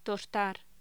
Locución: Tostar